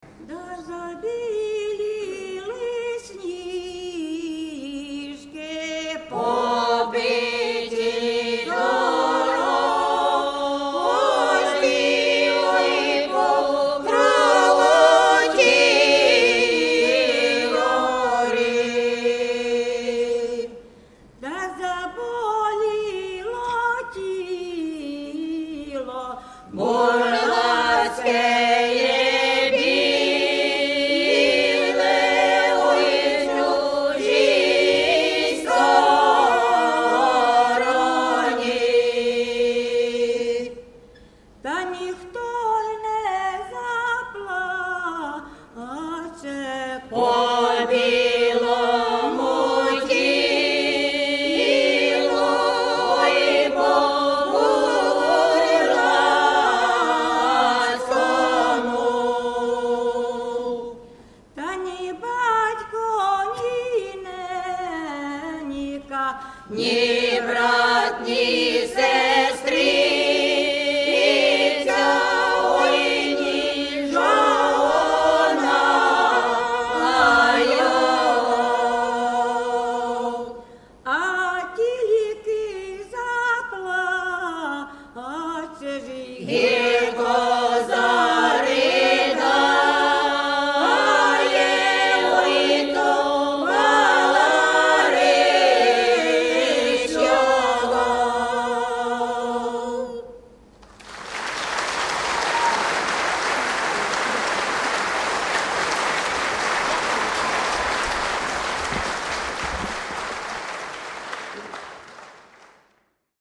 Complainte (village de Kriatchkivka, Ukraine)
Les flocons de neige ont blanchi les collines – complainte ukrainienne.